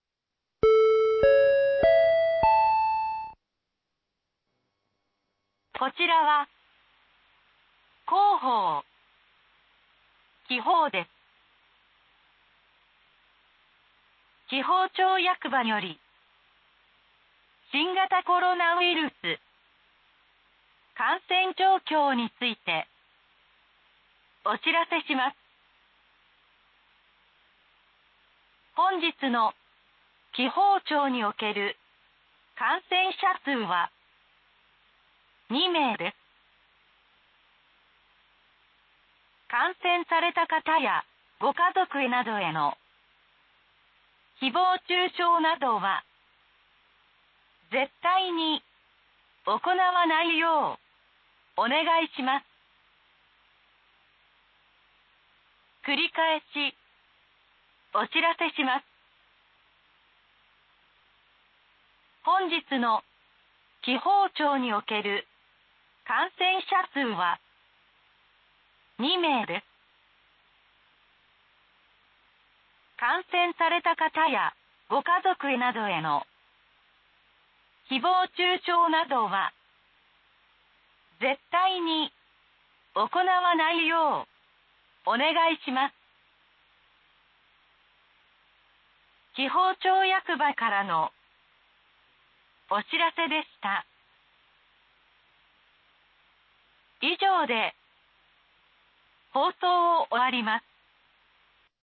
放送音声